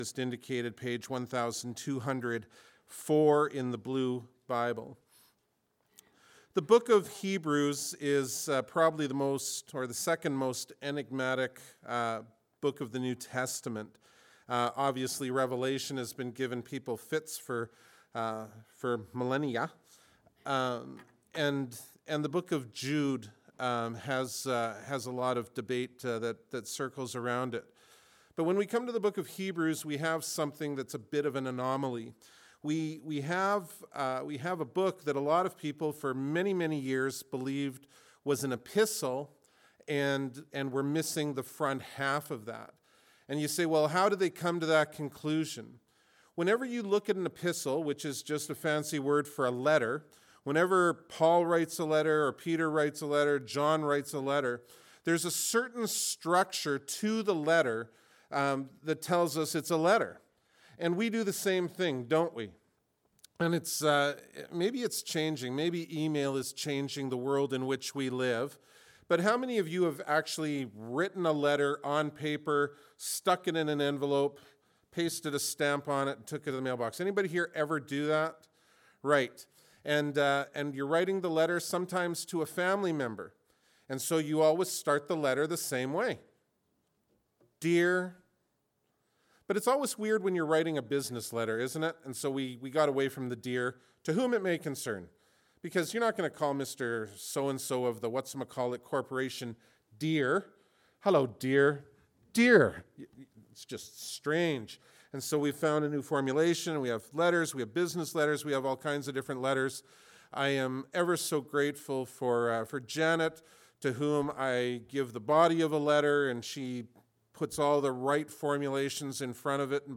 Bible Text: Hebrews 1:1-4 | Preacher